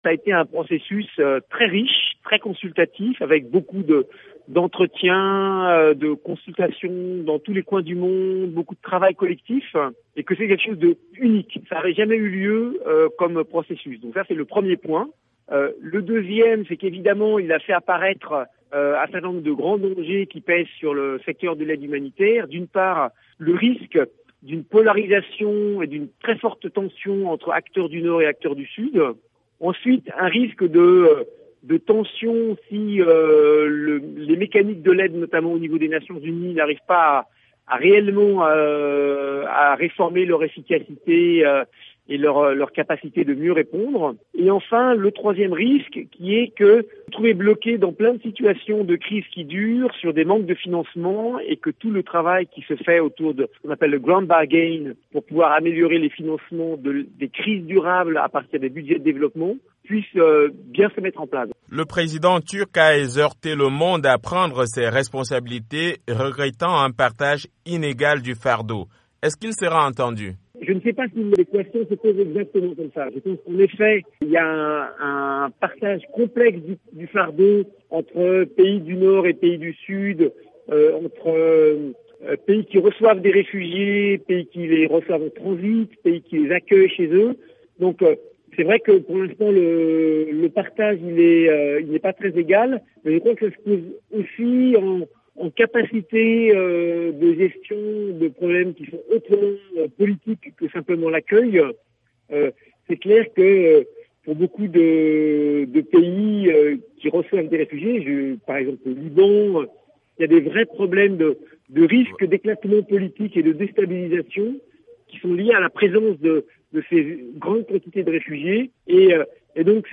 joint à Istanbul par VOA AFRIQUE